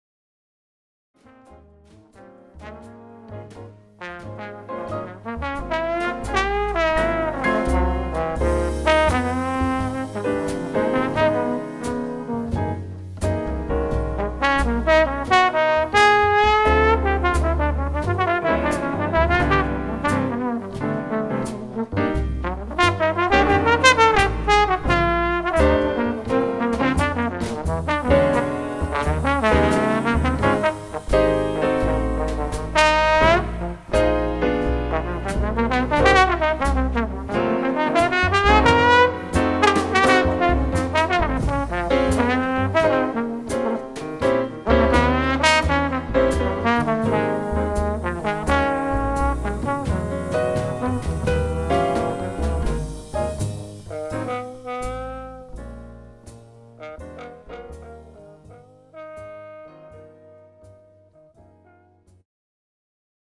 The Best In British Jazz
Recorded at Norden Farm Centre for the Arts, Jan 9th 2014